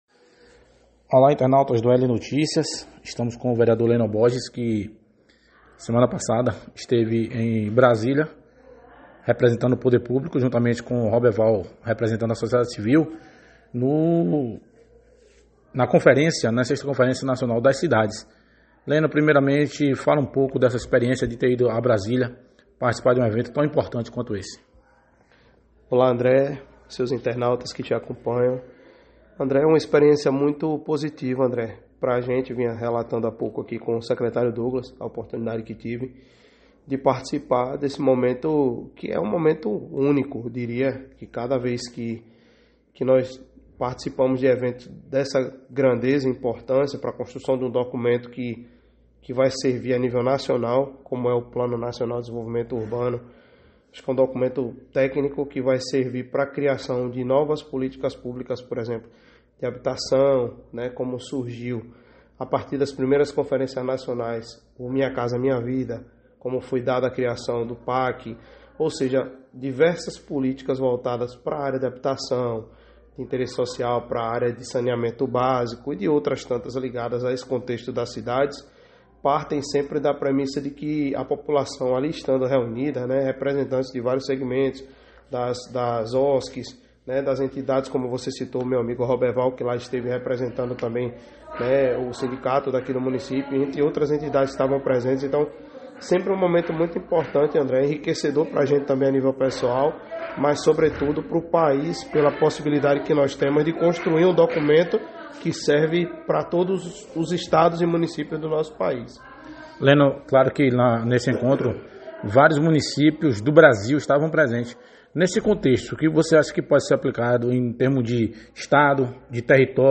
O vereador Lennon Borges em entrevista ao Site AL NOTÍCIAS avaliou esse momento importante.